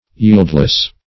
Yieldless \Yield"less\, a. Without yielding; unyielding.